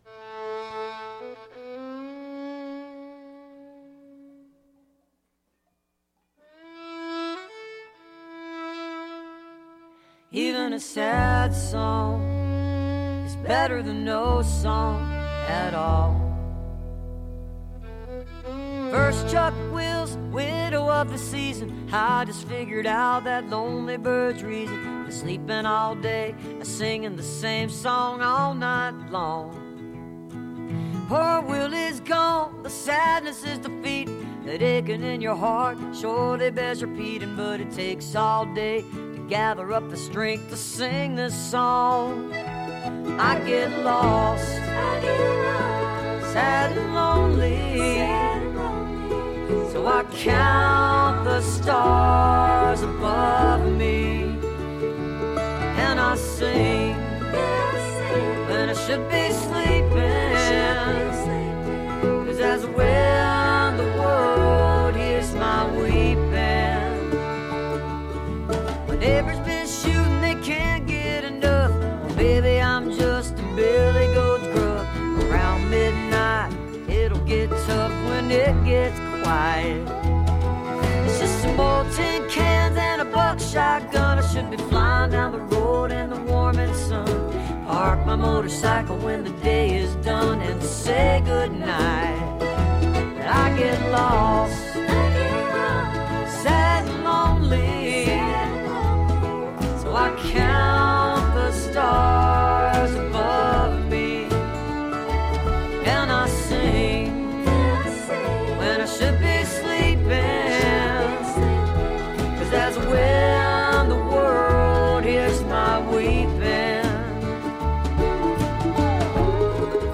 (captured from a webcast)
(album version)